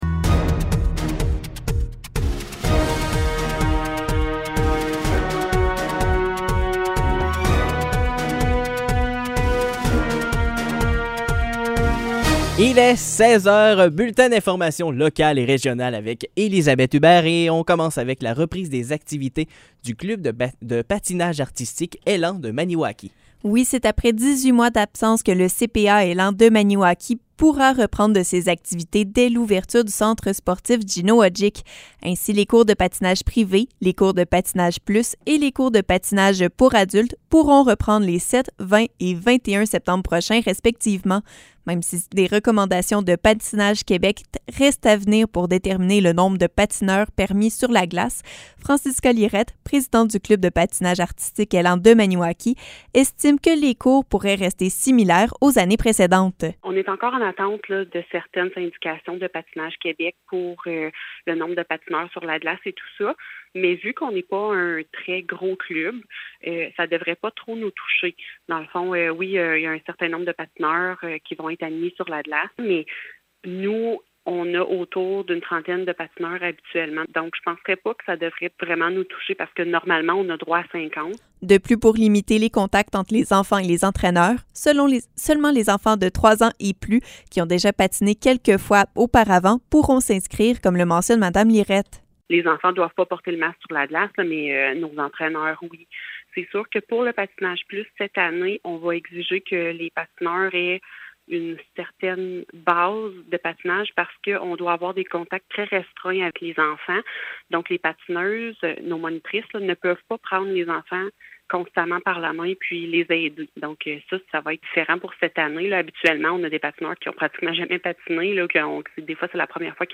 Nouvelles locales - 23 août 2021 - 16 h